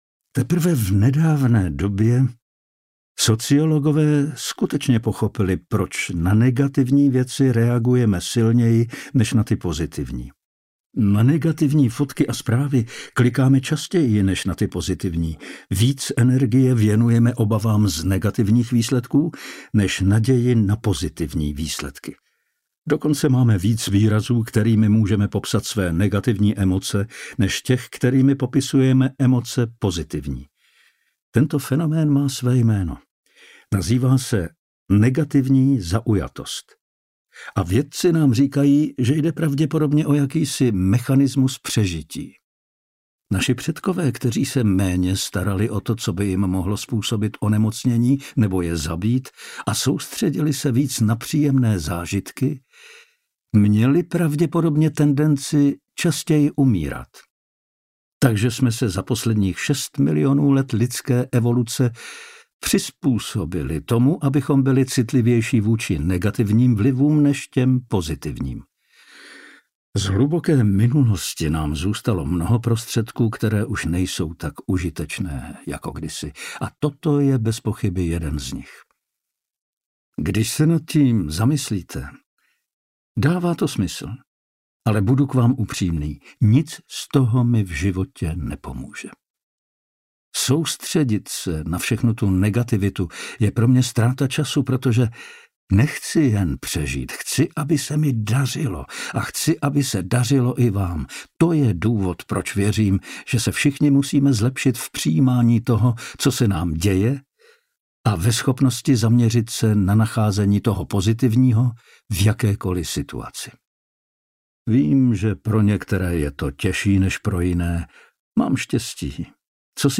audiokniha
Čte: Pavel Soukup